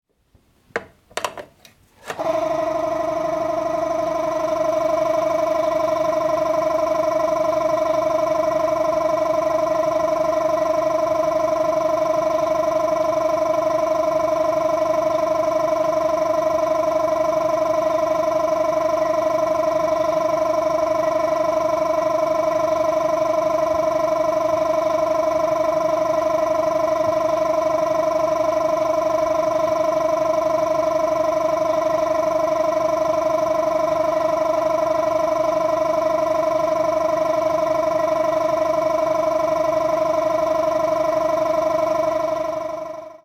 Old Air Compressor Start
In the audio you can hear the start up sound of an old air compressor. Air compressors are devices that convert power into energy.
The sound you’ll hear is the pressure tank filling up.
• Air compressor
• electrical motor